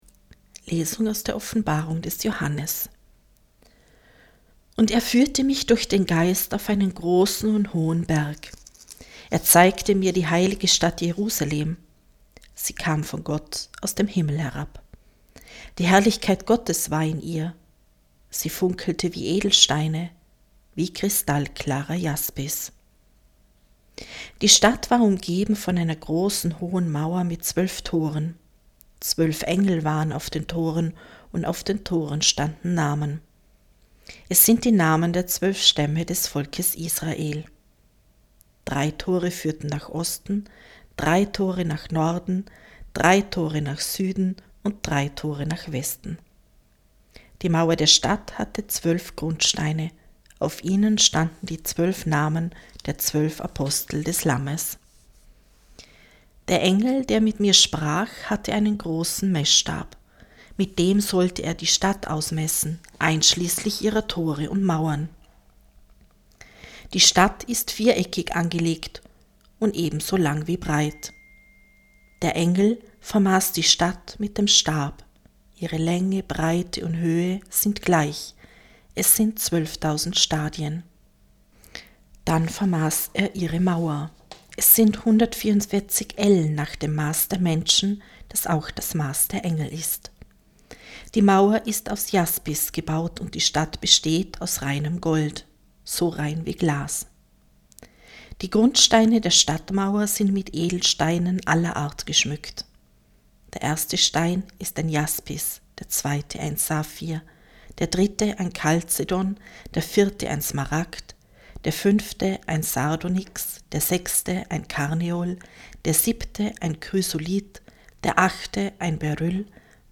Wenn Sie den Text der 2. Lesung aus der Offenbarung des Johannes: